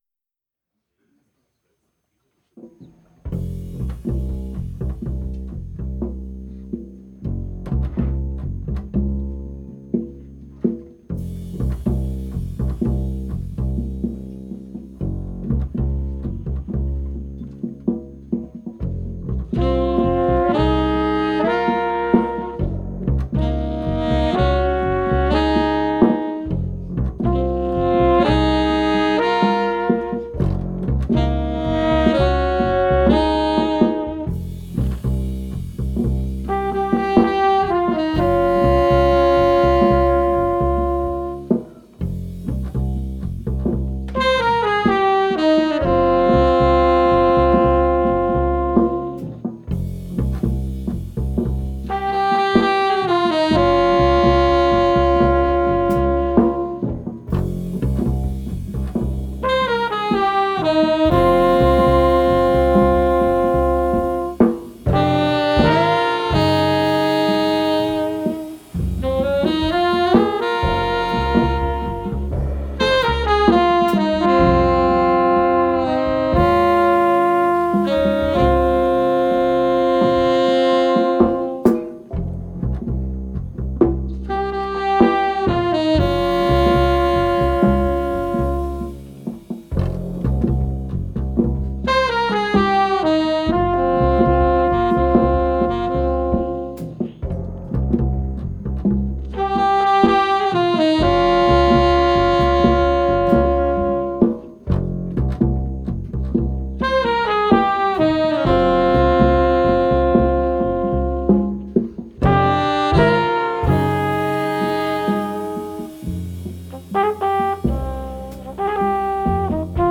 bass clarinet, soprano sax, tenor sax
trumpet, flugelhorn
double bass